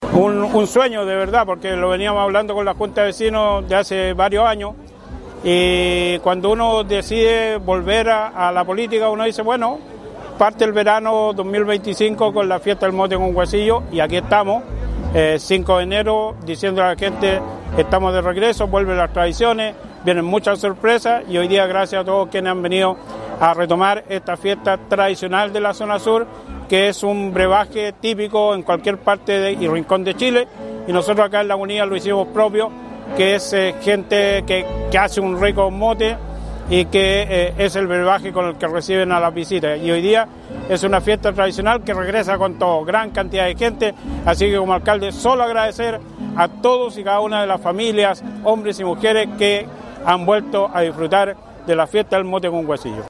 CARLOS VALENZUELA ALCALDE DE CONSTITUCION
001-CARLOS-VALENZUELA-ALCALDE-DE-CONSTITUCION_01.mp3